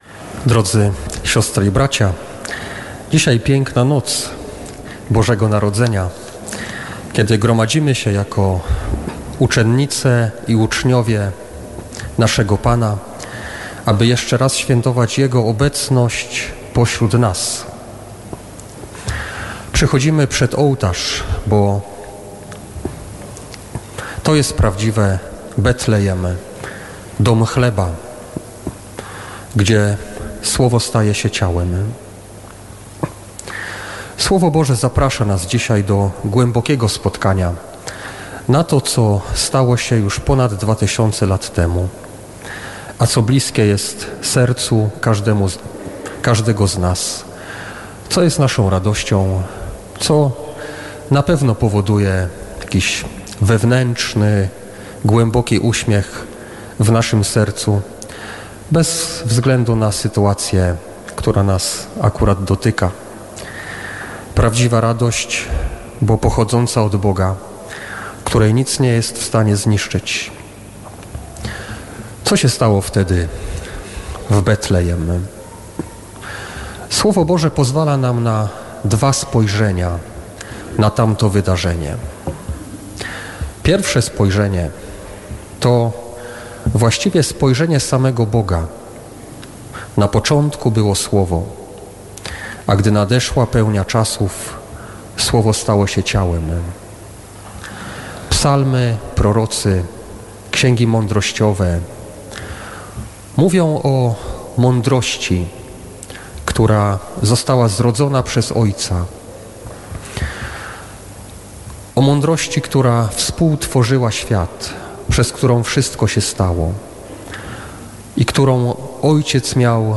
Pasterka w Katedrze Wrocławskiej
W noc Bożego Narodzenia w Katedrze Wrocławskiej ks. bp Maciej Małyga, biskup pomocniczy archidiecezji wrocławskiej, przewodniczył uroczystej Pasterce.